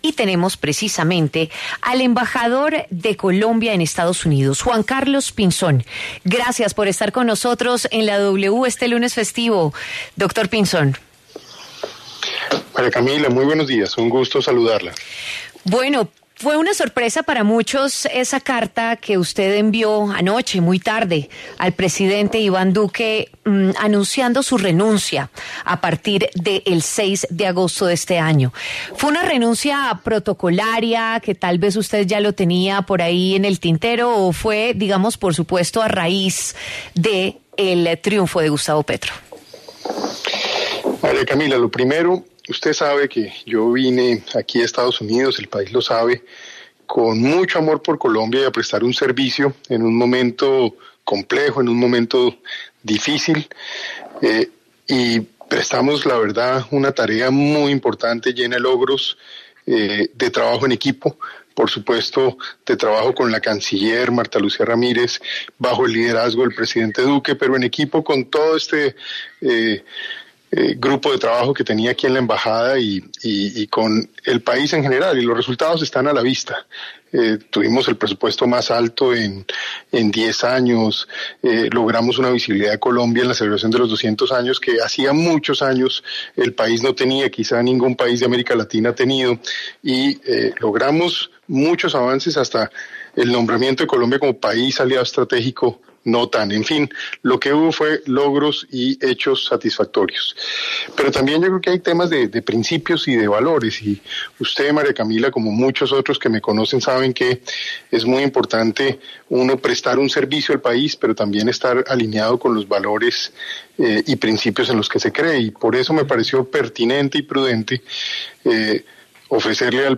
Juan Carlos Pinzón habló en W Radio sobre las razones que lo llevaron a tomar la decisión de renunciar y de las relaciones que busca tener Joe Biden con el nuevo Gobierno colombiano.